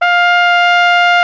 Index of /90_sSampleCDs/Roland L-CD702/VOL-2/BRS_Flugelhorn/BRS_Flugelhorn 1